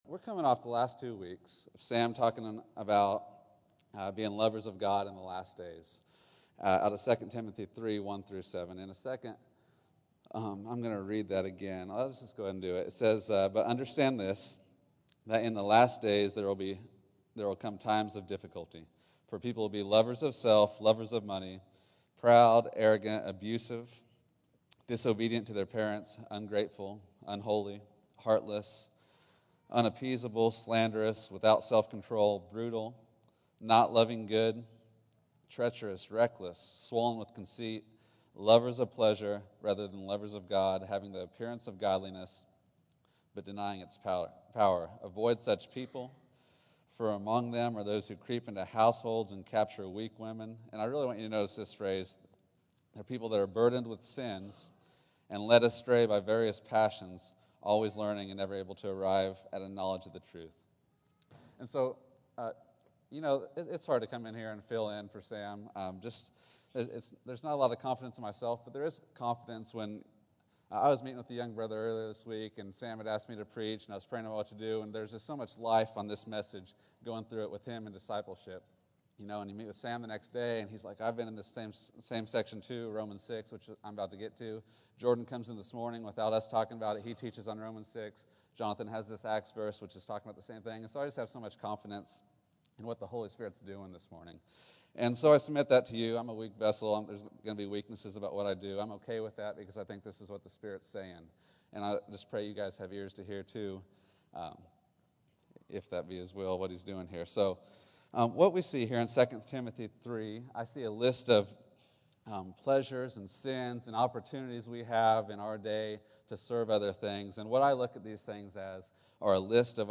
Freedom to Obey November 17, 2013 Category: Sermons | Location: El Dorado Back to the Resource Library The finished work of Christ gives us freedom to obey from the heart.